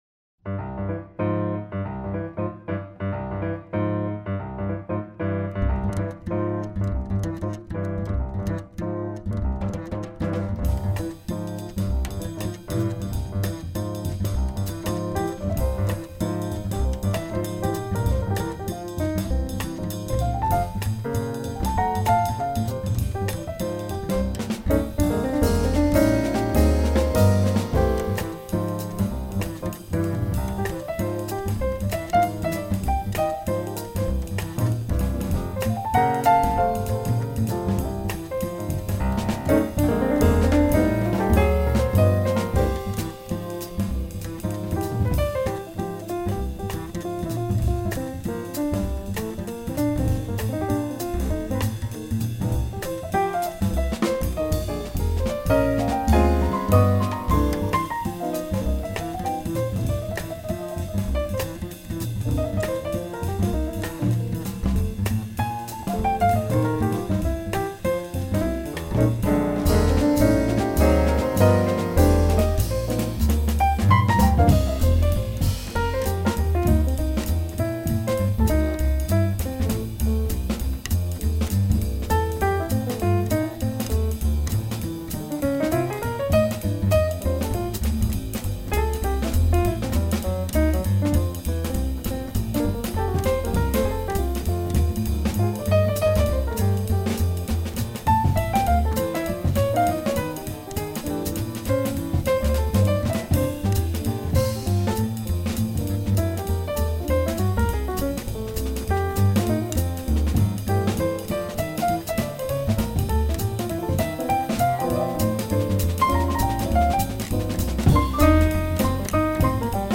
I just pulled out a CD by one of my favorite jazz trios
bassist
drummer
The upbeat composition